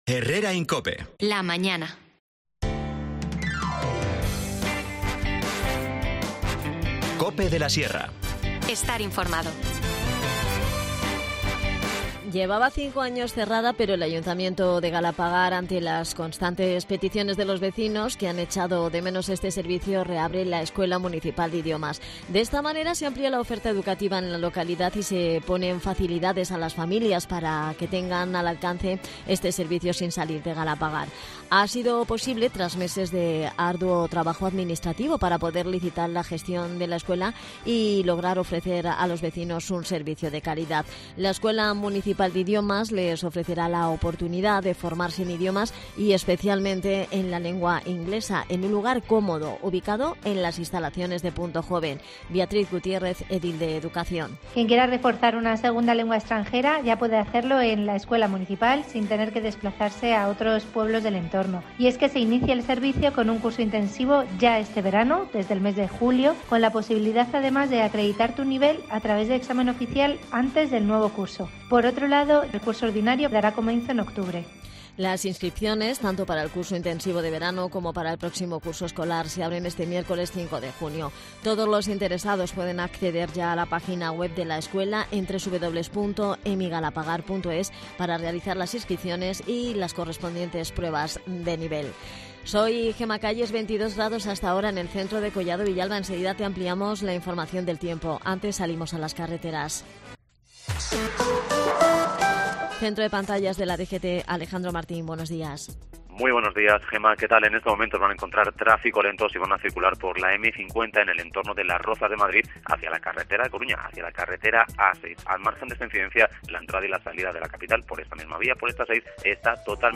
Repasamos el programa de fiestas con Ramón Pavón, concejal de Festejos.